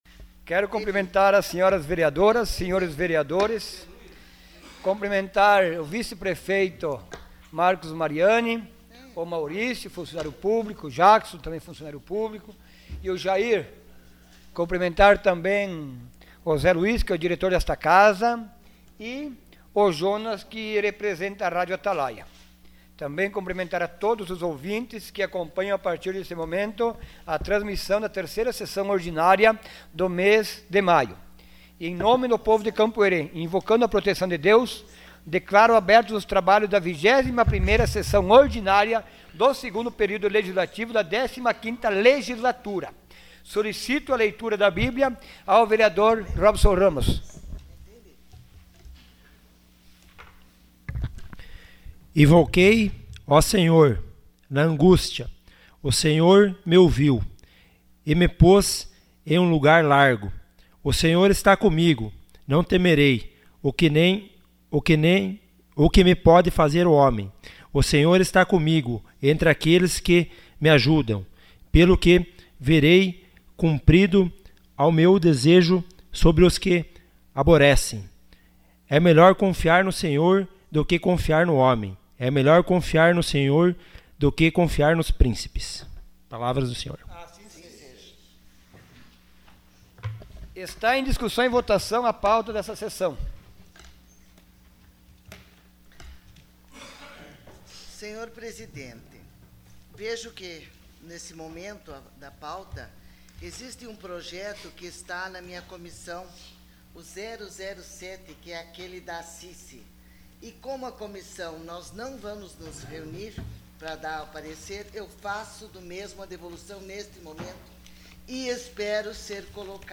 Sessão Ordinária dia 17 de maio de 2018.